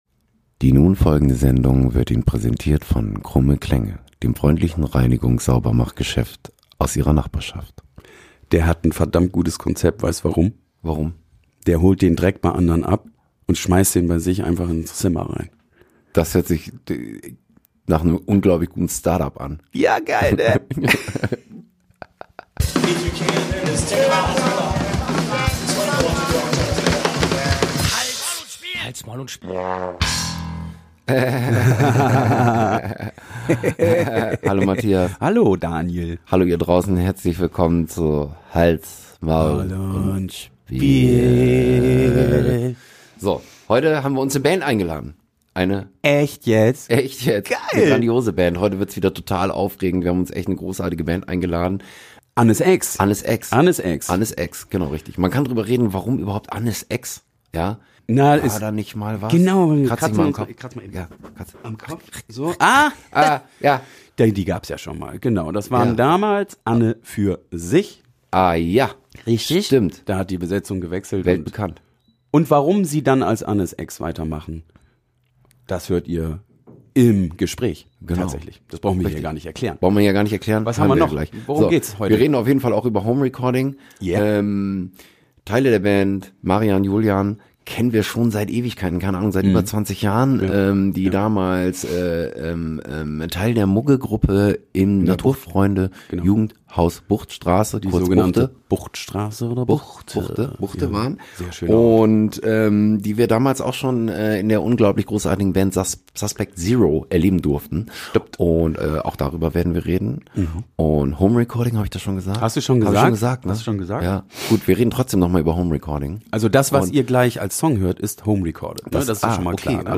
Januar 2023 Nächste Episode download Beschreibung Teilen Abonnieren LIVE aus der HoF-BaR Heute mit: ANNES.EX (Deutschpunkrock) aus Bremen Was ist diesmal los?